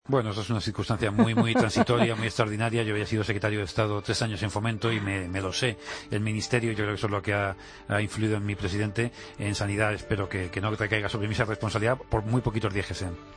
Escucha al entonces ministro de Justicia en funciones, Rafael Catalá, hablar sobre su futuro político en 'La Linterna' el pasado 1 de agosto de 2016